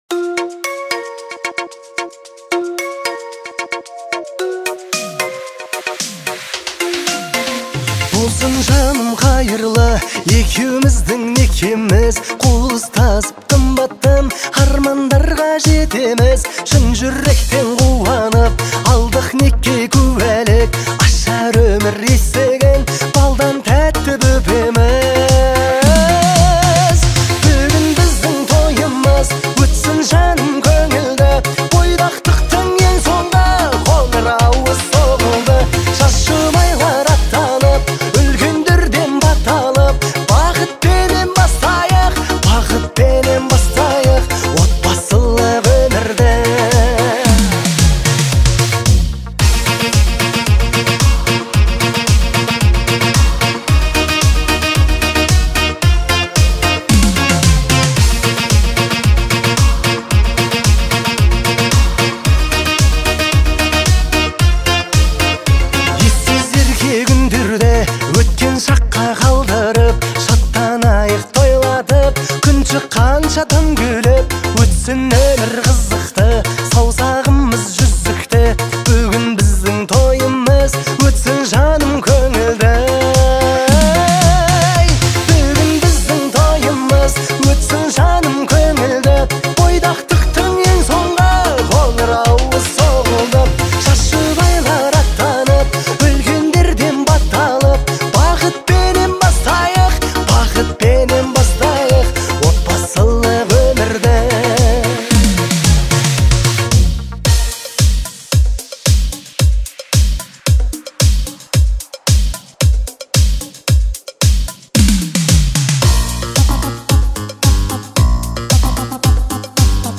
Категория: Казахские,